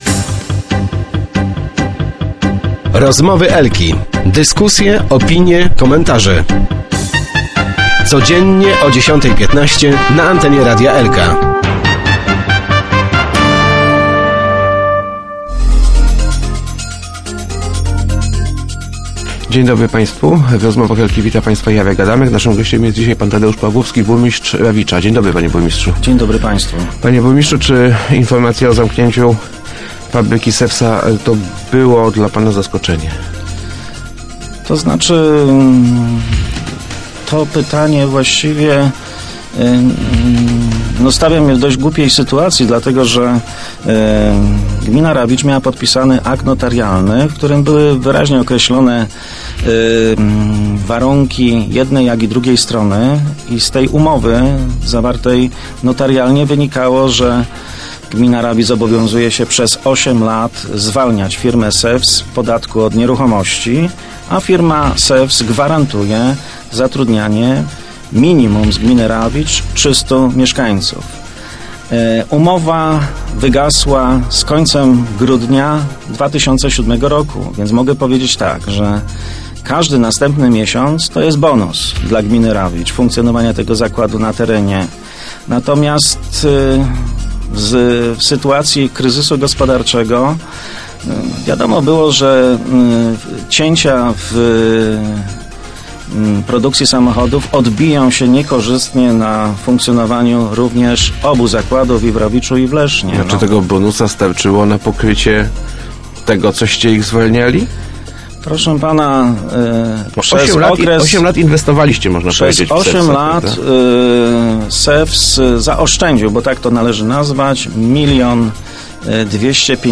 Już pod koniec ubiegłego roku otrzymywałem sygnały o tym, że SEWS chce zlikwidować produkcję w Rawiczu – mówił w Rozmowach Elki burmistrz Tadeusz Pawłowski.